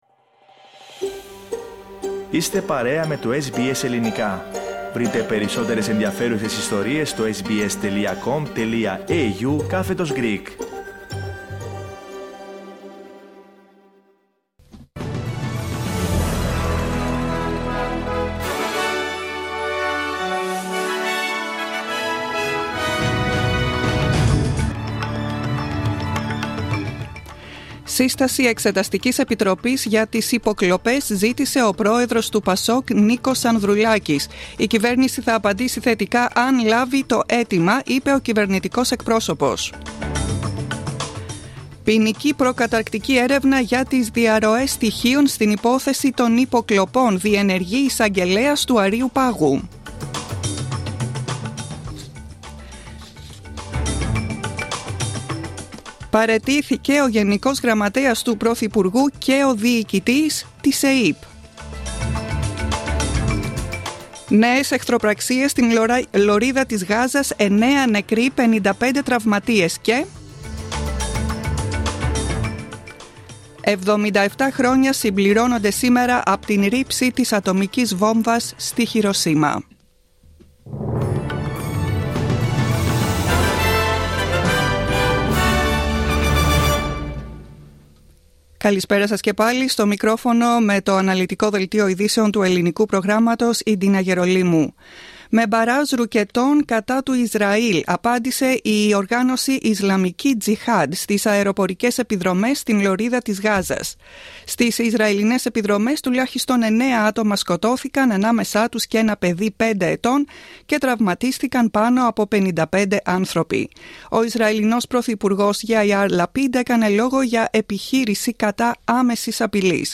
Δελτίο ειδήσεων, 06.08.2022
Ακούστε το αναλυτικό δελτίο ειδήσεων στα ελληνικά.